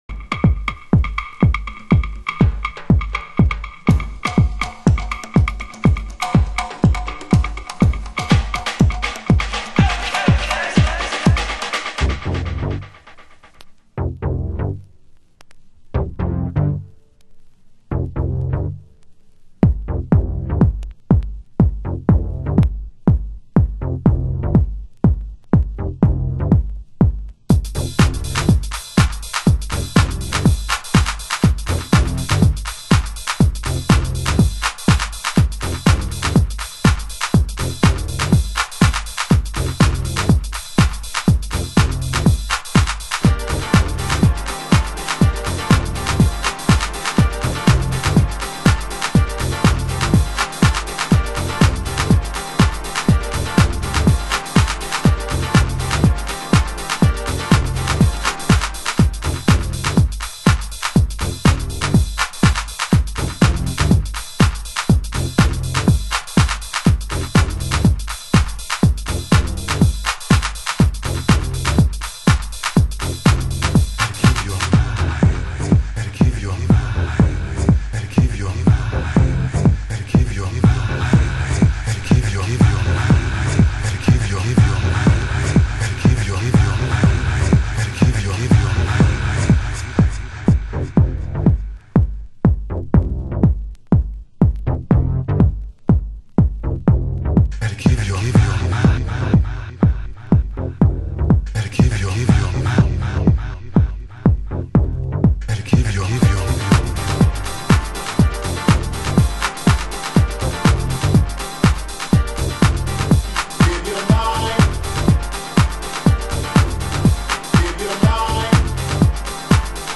(Vocal Mix)
(Deep & Dubby)